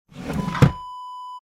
Dresser Drawer Close Wav Sound Effect #3
Description: The sound of a wooden dresser drawer being closed
A beep sound is embedded in the audio preview file but it is not present in the high resolution downloadable wav file.
Keywords: wooden, dresser, drawer, push, pushing, close, closing
drawer-dresser-close-preview-3.mp3